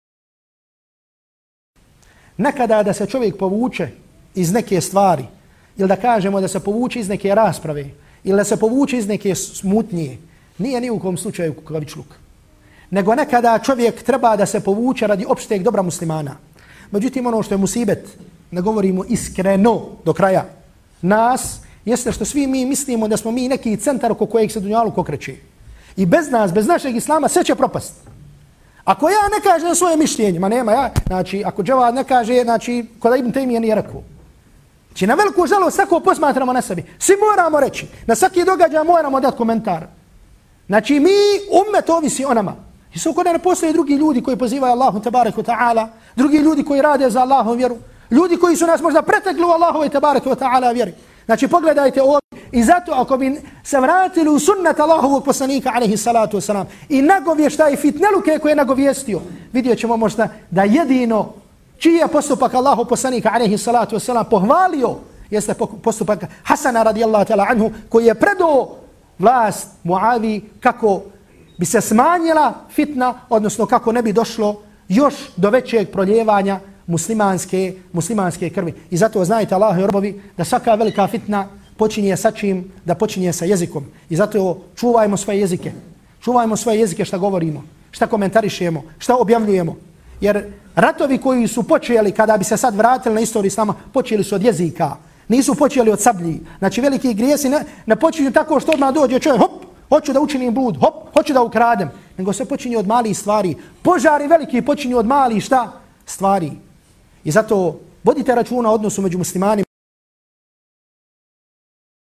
Posušajte audio isječak sa predavanja: